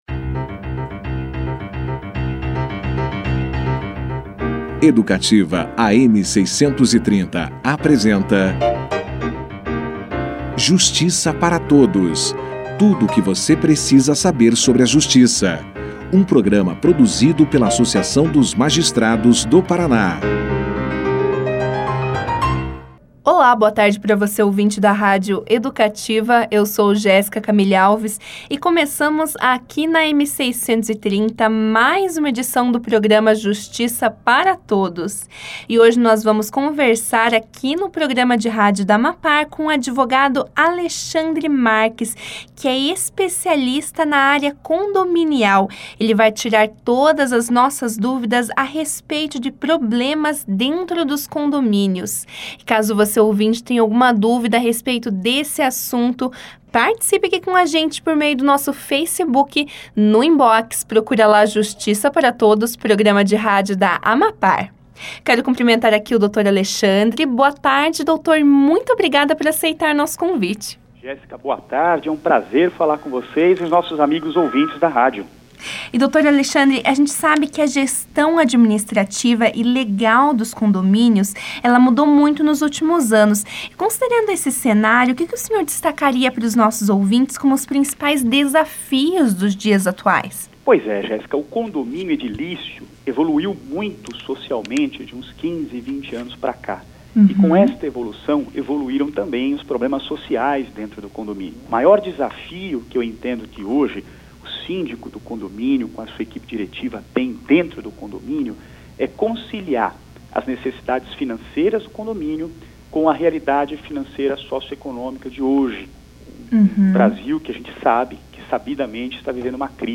Para finalizar, o advogado comentou sobre as perspectivas da gestão condominial para o futuro e deu exemplos de práticas que podem melhorar a convivência entre os moradores. Confira aqui a entrevista na íntegra.